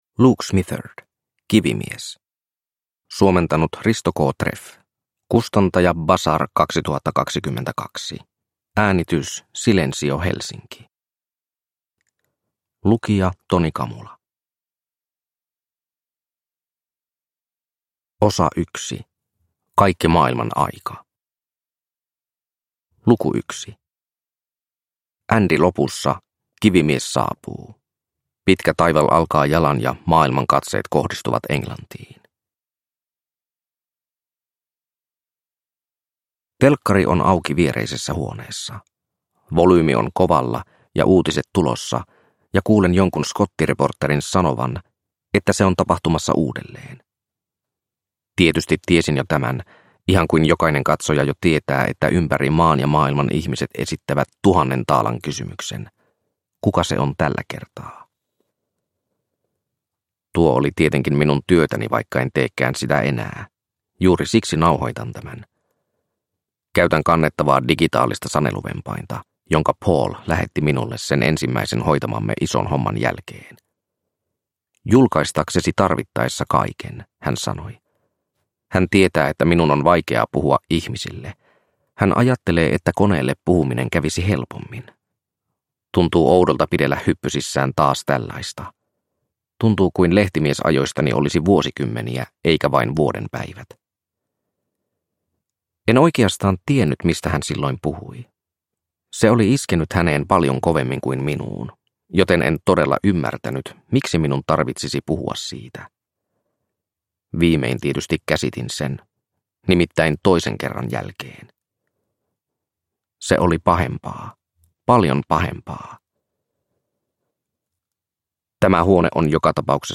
Kivimies – Ljudbok – Laddas ner